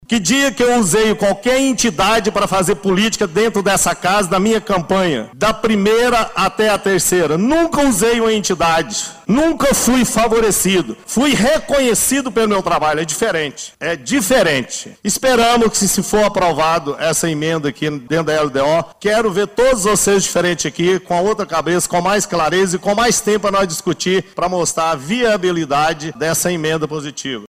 Clique e Ouça vereador Dilé